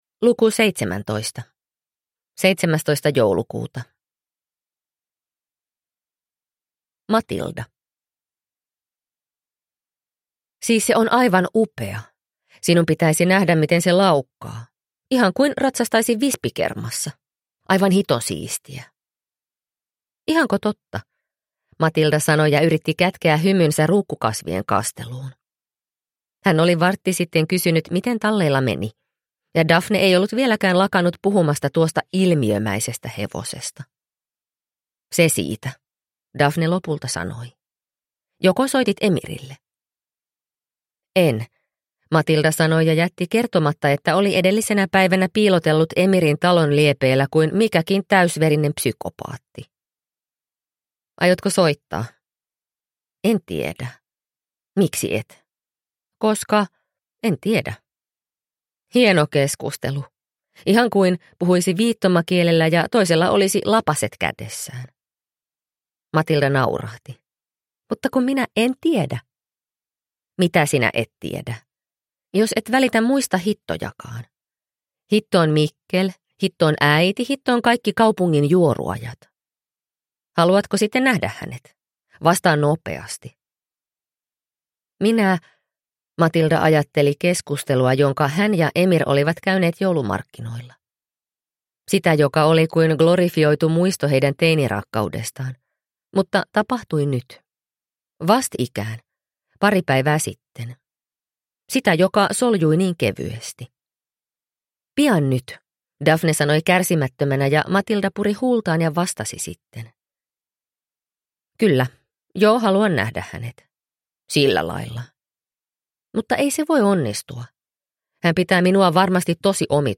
Sankt Annan joulu – Ljudbok – Laddas ner